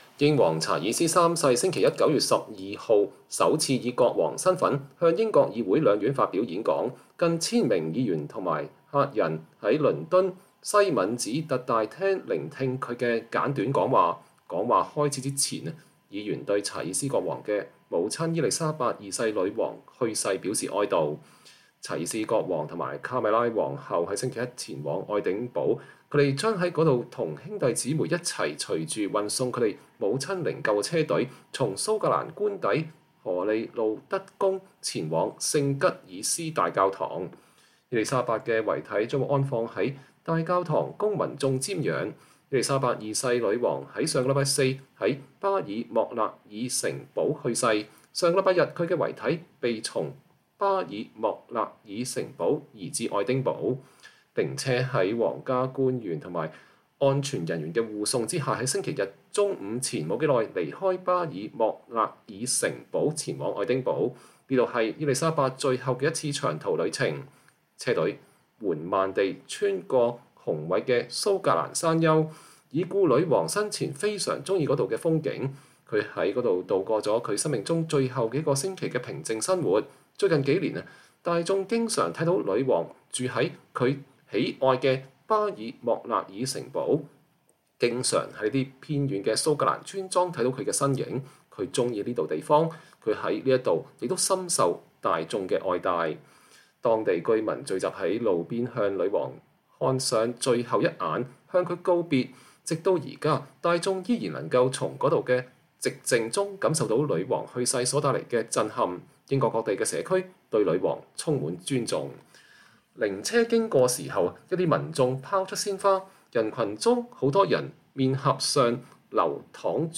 英王查爾斯三世週一（9月12日）首次以國王身份向英國議會兩院發表演講。近千名議員和客人在倫敦威斯敏斯特大廳聆聽了他的簡短講話。講話開始前，議員對查爾斯國王的母親伊麗莎白女王的去世表示哀悼。